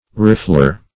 riffler - definition of riffler - synonyms, pronunciation, spelling from Free Dictionary Search Result for " riffler" : The Collaborative International Dictionary of English v.0.48: Riffler \Rif"fler\, n. [See Riffle .] A curved file used in carving wool and marble.